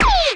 impact_projectile_001.wav